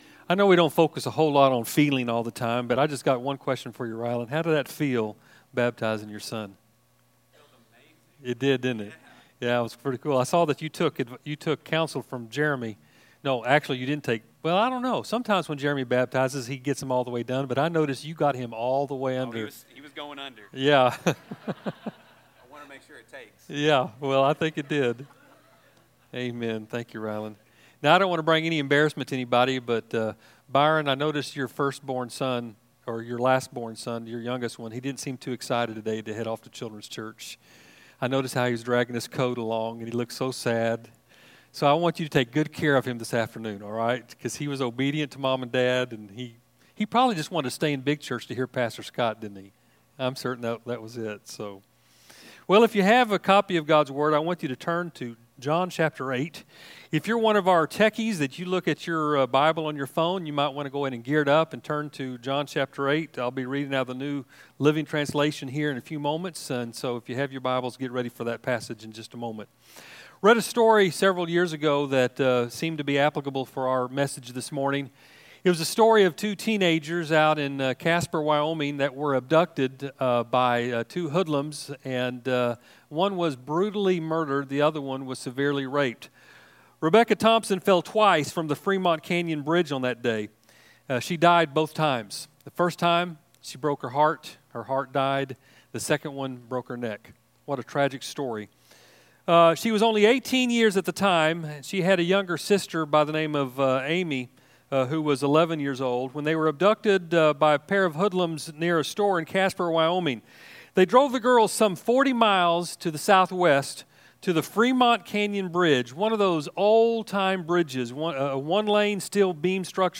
A lesson on giving through the lens of the Macedonia Church.